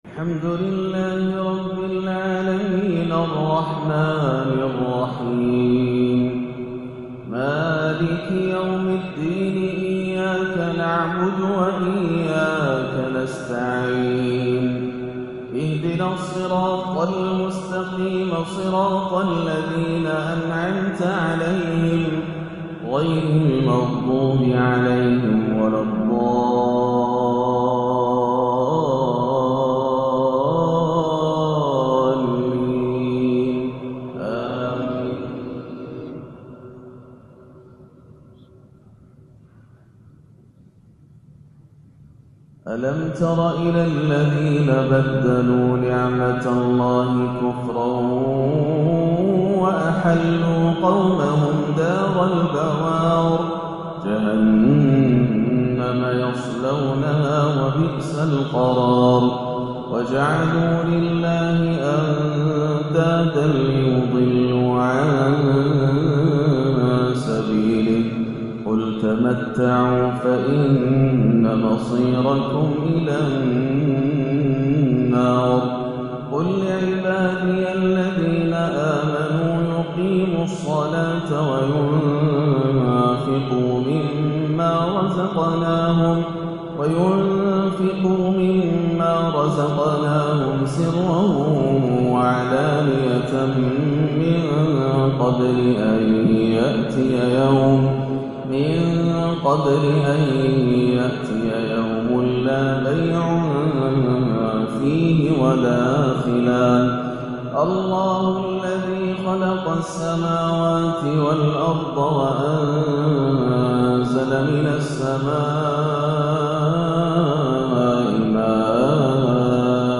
ما تيسر من سورة "إبراهيم" بالحجاز والرست 9-7-1439هـ > عام 1439 > الفروض - تلاوات ياسر الدوسري